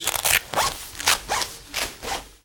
household
Cloth Rain Coat Velcro and Zipper